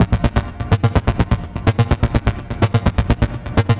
Retro Dirt 126.wav